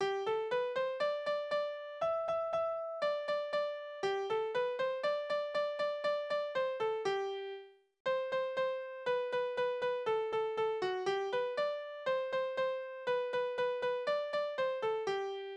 Necklieder: Pastor sin Kauh
Tonart: G-Dur
Taktart: 2/4
Tonumfang: kleine Septime
Besetzung: vokal